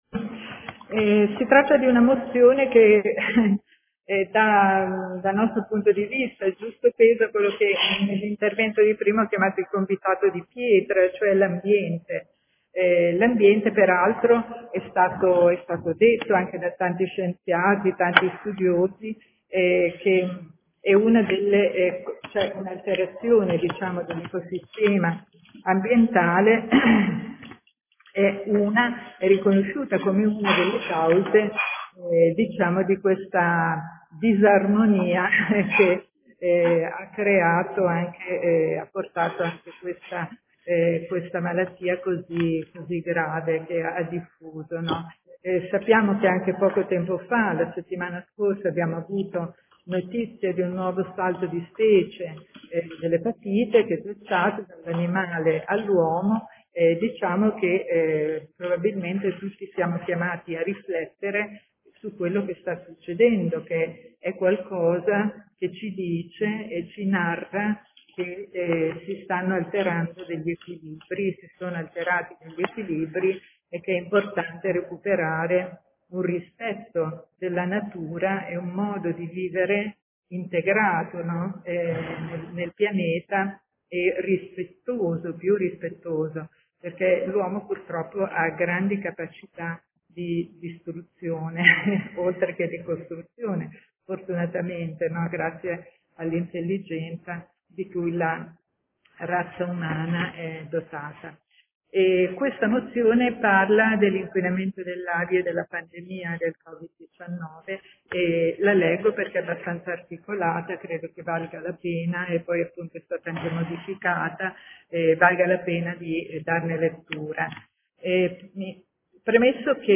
Paola Aime — Sito Audio Consiglio Comunale